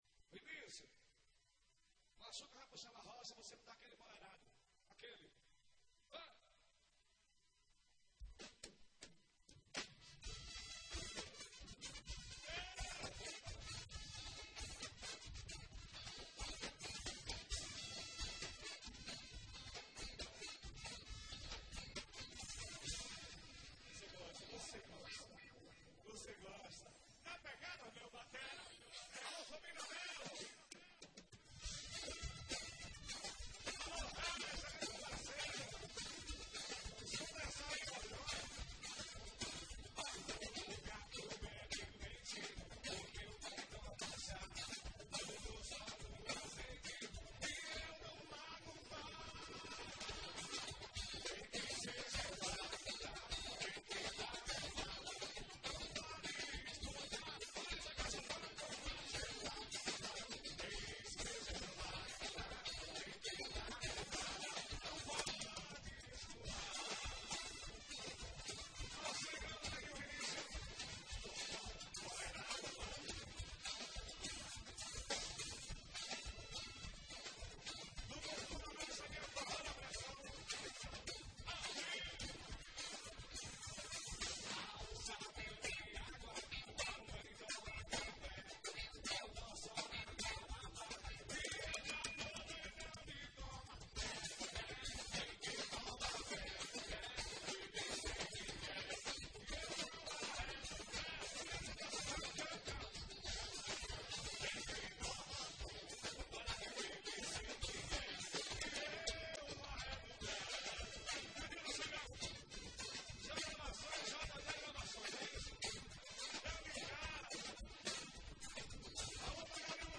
Composição: FORRÓ.